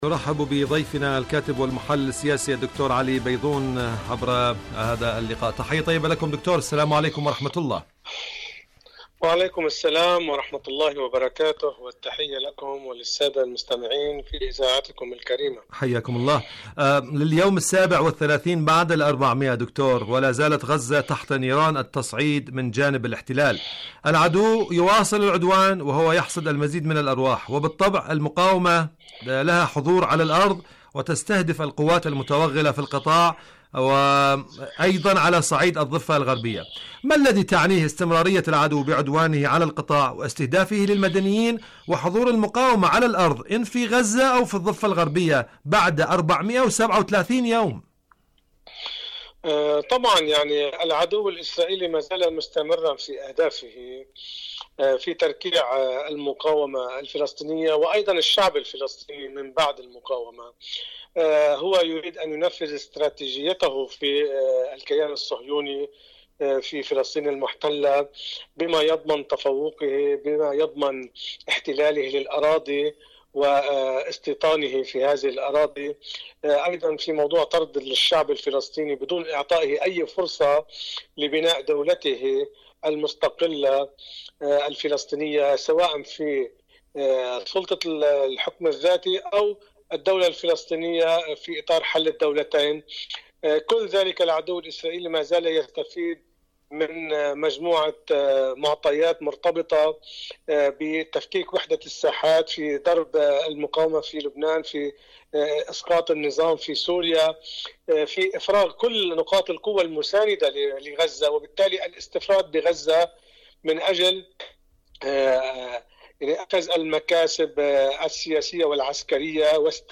مقابلات برامج إذاعة طهران العربية مقابلات إذاعية برنامج فلسطين اليوم القدس الشريف تمدد العدوان وأثقال الميدان شاركوا هذا الخبر مع أصدقائكم ذات صلة مواقف طهران من تهديدات ترامب..